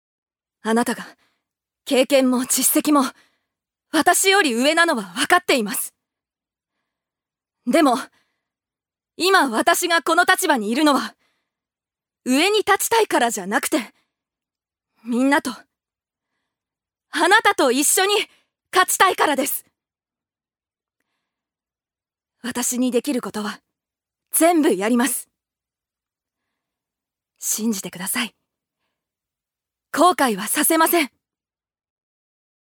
ジュニア：女性
セリフ１